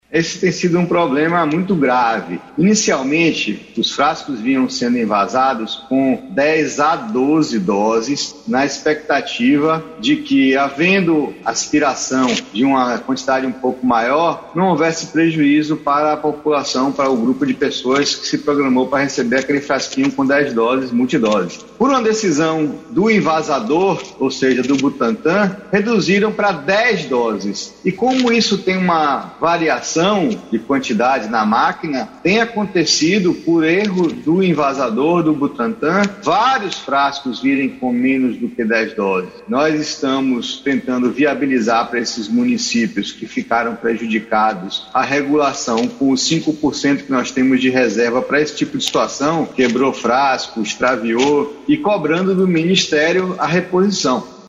Butantan reduziu doses de vacinas nos frascos, explica secretário da saúde
Em cerca de 30 municípios baianos, foi identificado que alguns frascos de vacinas traziam quantidades menores que o descrito no rótulo. O secretário estadual da saúde, Fábio Vilas-Boas, explica que isso ocorreu por um erro do Instituto Butantan, que faz o envasamento das doses.